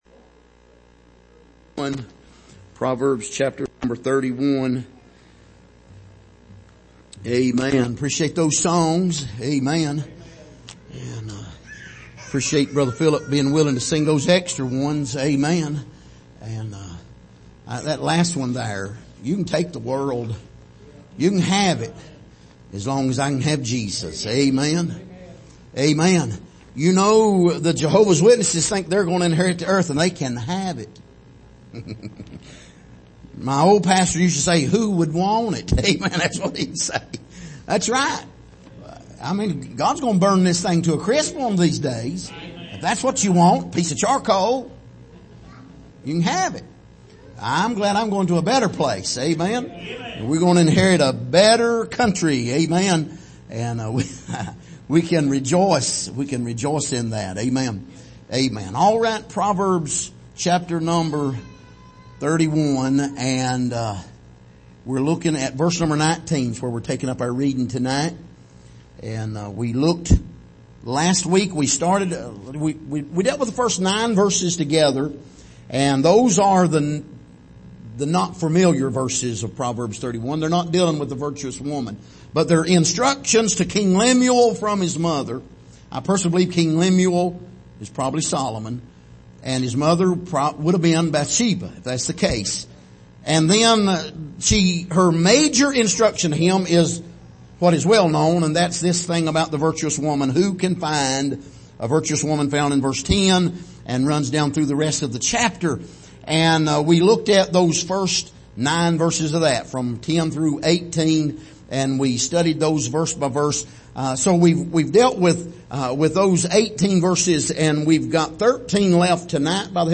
Passage: Proverbs 31:19-25 Service: Sunday Evening